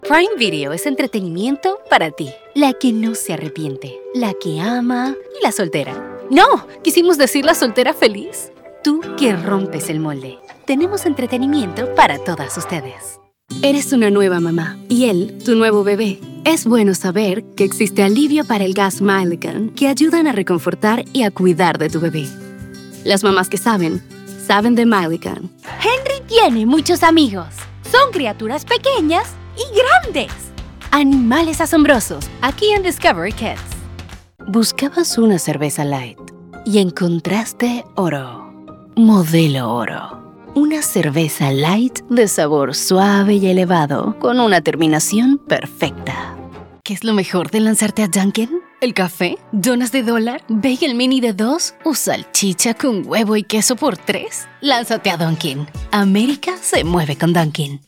English (Latin American Accent)
Adult (30-50) | Yng Adult (18-29)